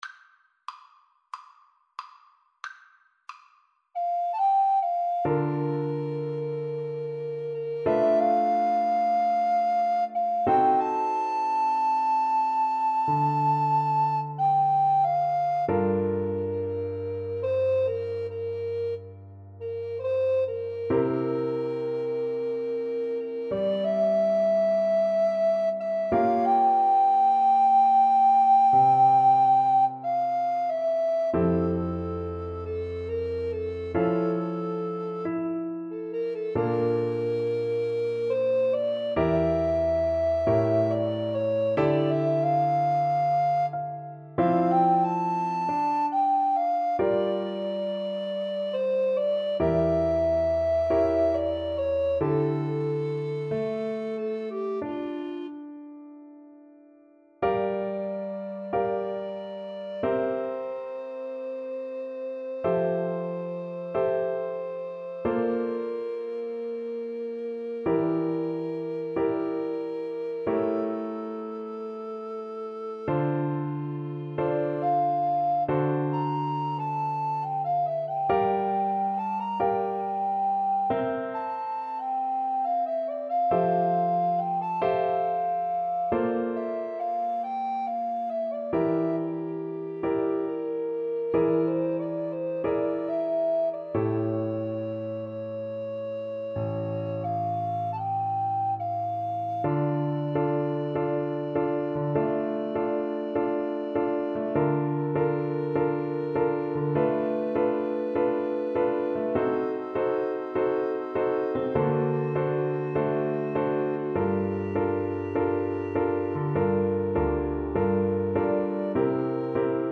4/4 (View more 4/4 Music)
Andante =c.92
Jazz (View more Jazz Alto Recorder Duet Music)